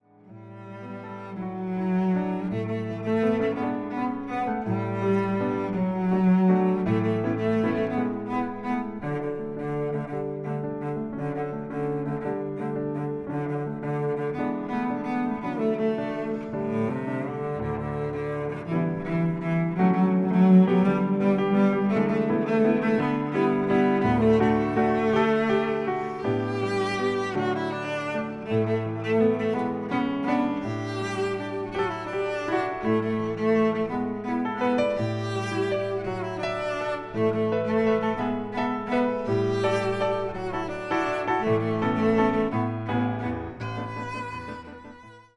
チェロのたおやかな音色による実直な美の結晶であること。
チェロ
ピアノ